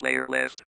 voice_playerleft.ogg